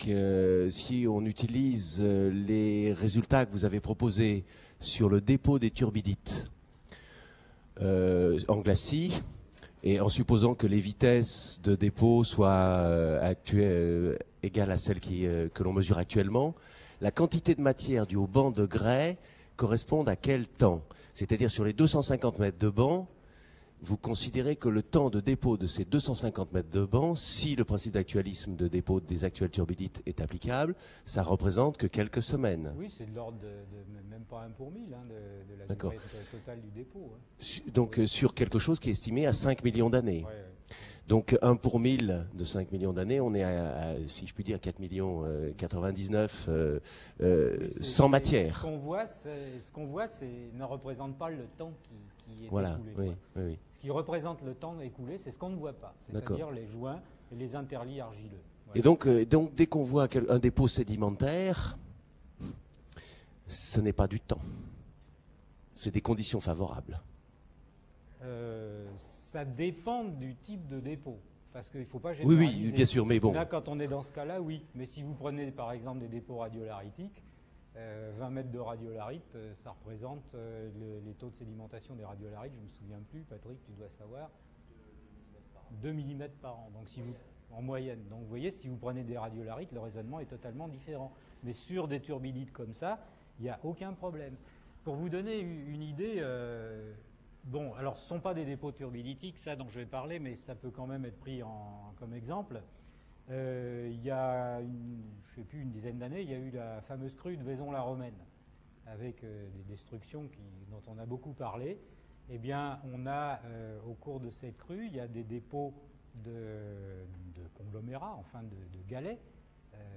Réponses à quelques questions posées par le public à l’issue de la conférence.